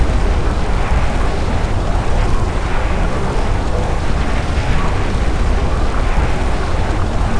volcano_loop.wav